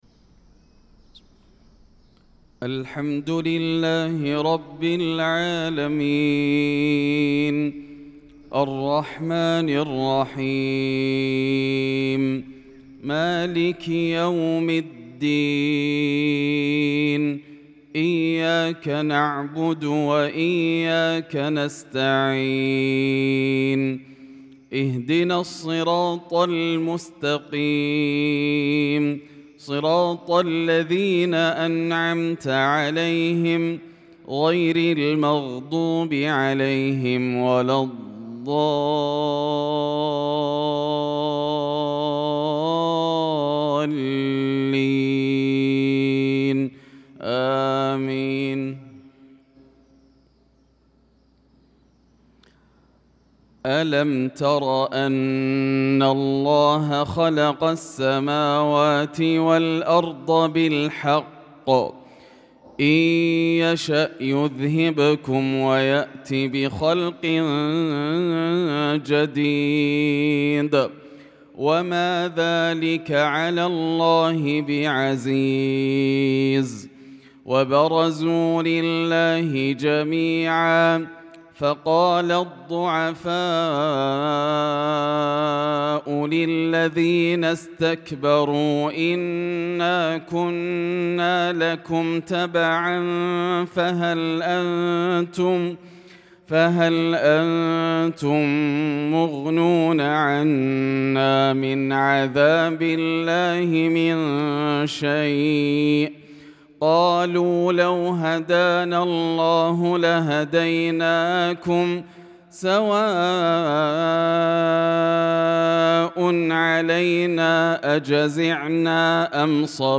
صلاة الفجر للقارئ ياسر الدوسري 23 شوال 1445 هـ
تِلَاوَات الْحَرَمَيْن .